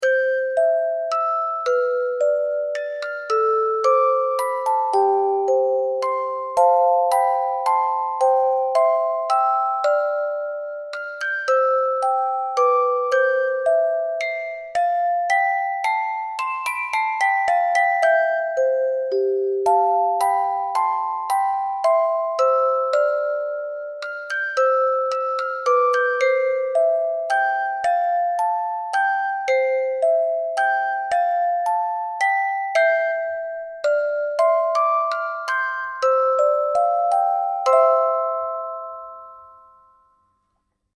落ち着いたイメージ, オルゴール素材.